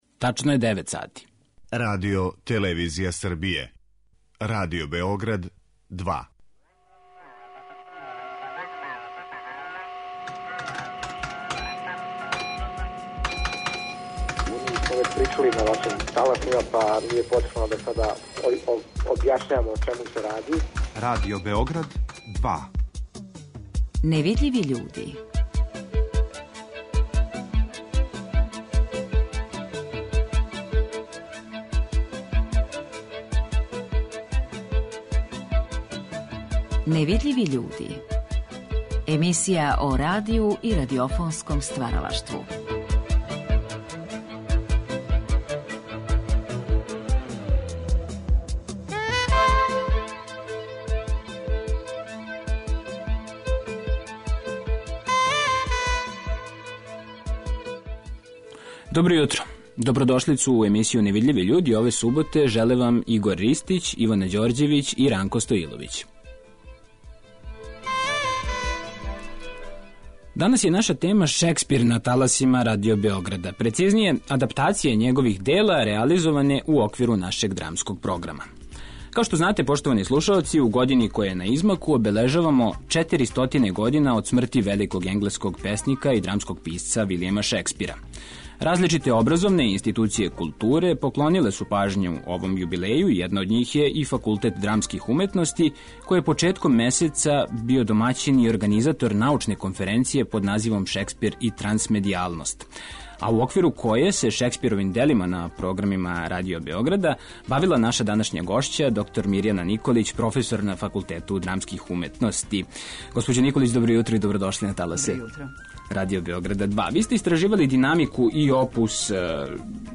Емитоваћемо и одломке из неких од најзначајнијих Шекспирових дела реализованих на нашим таласима, а биће речи и о позитивним примерима адаптација овог драматичара у искуствима других европских радио-станица.